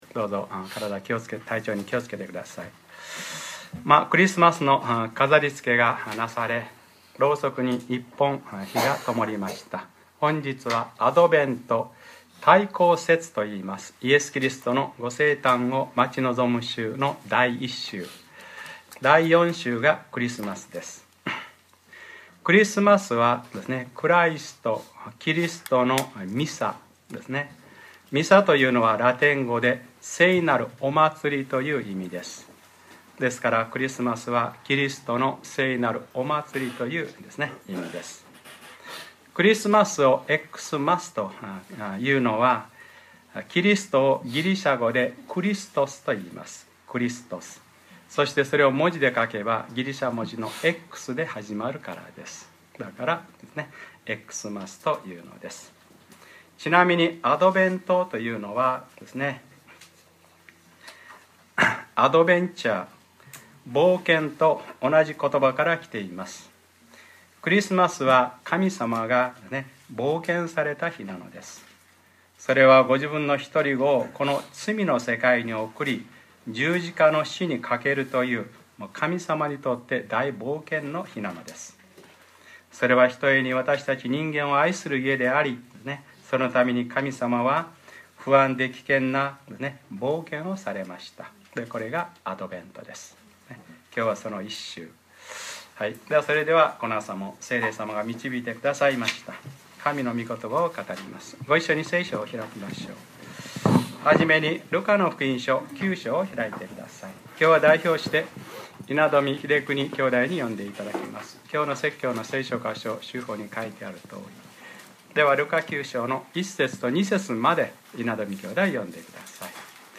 2013年12月01日（日）礼拝説教 『ルカｰ３０：能力付与・伝道と増殖』